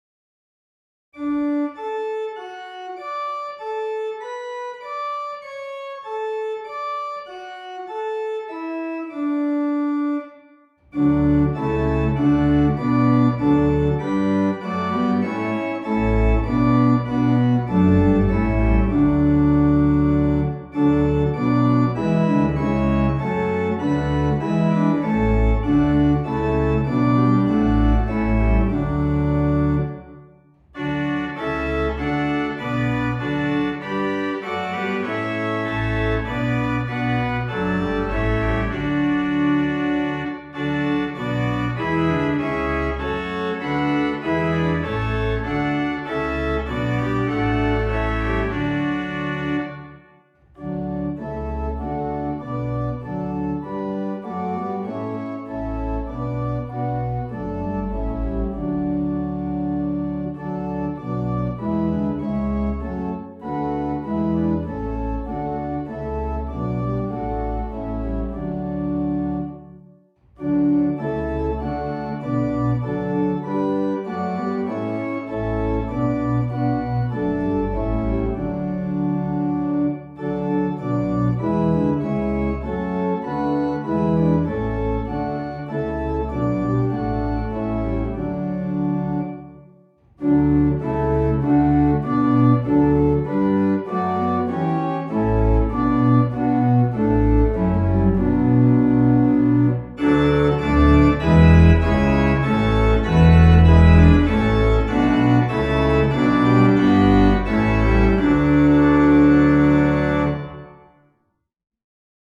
Organ: St Anne’s, Moseley